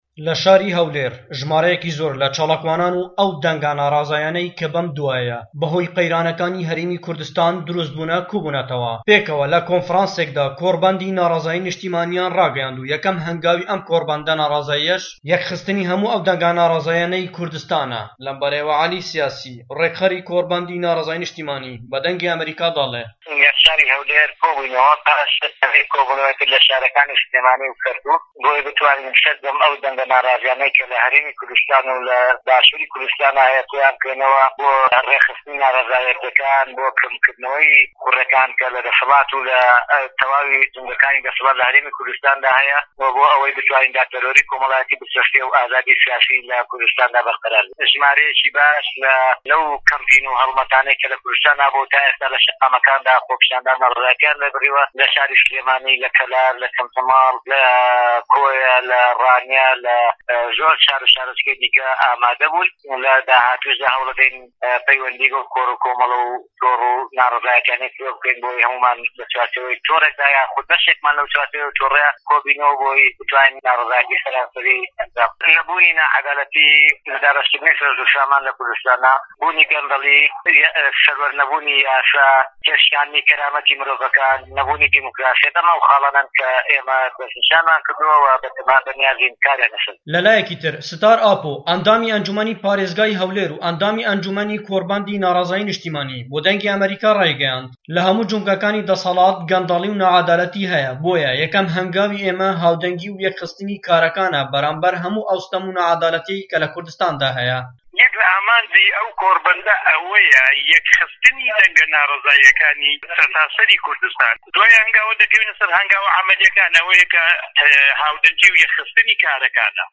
دەقی ڕاپۆرتەکەی پەیامنێرمان